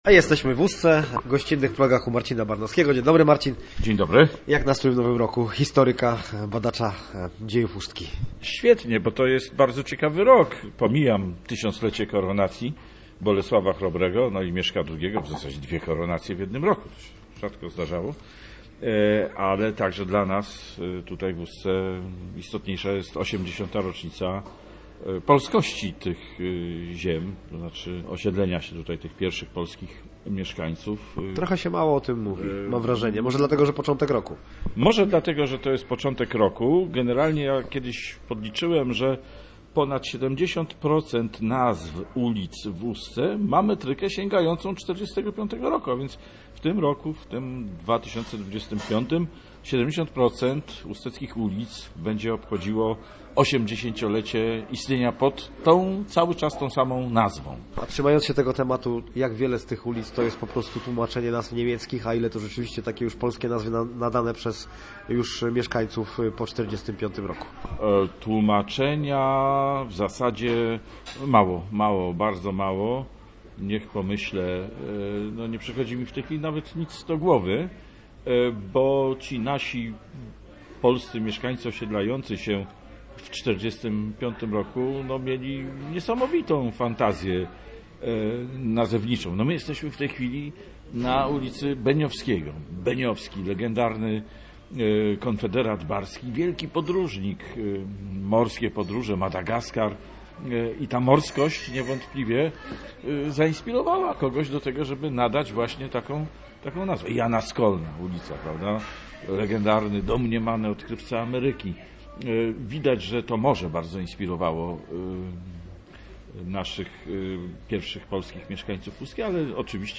O kurorcie znawca dziejów Ustki opowiada w rozmowie